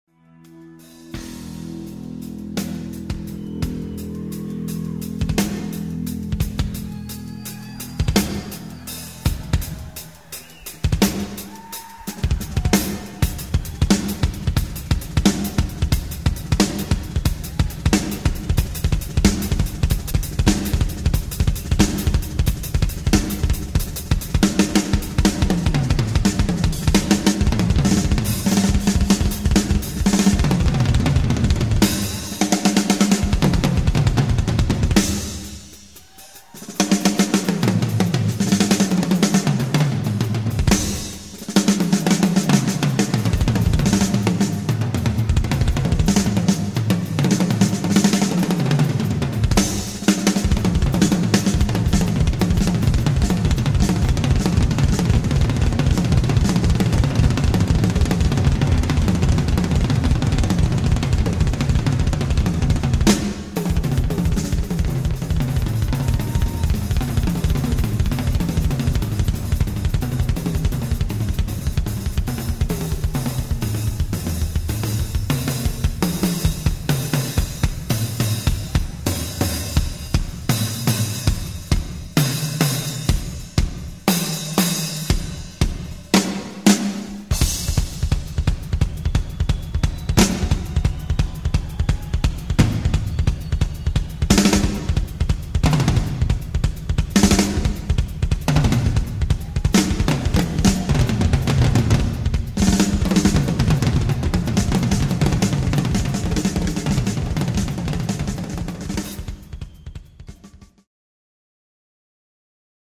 ES UN SOLO